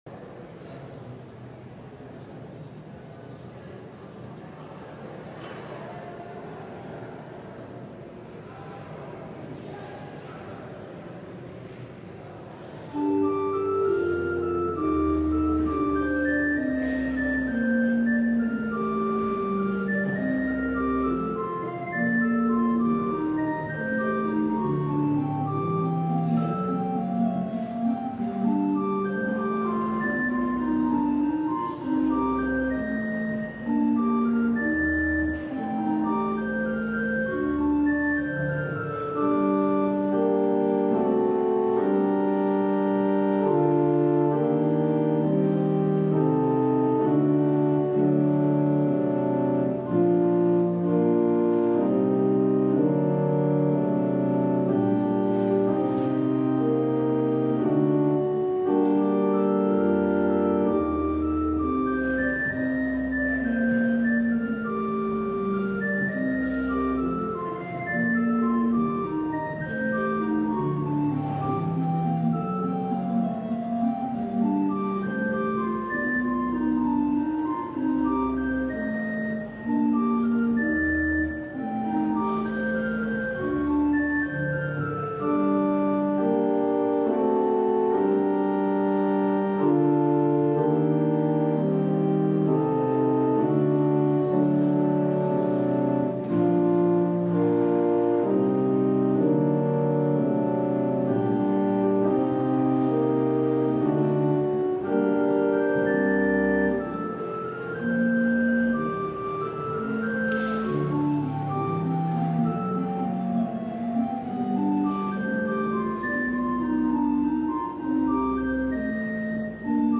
LIVE Evening Worship Service - Cry from the Cave
Congregational singing—of both traditional hymns and newer ones—is typically supported by our pipe organ.